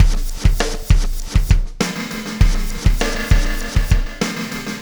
Black Hole Beat 01.wav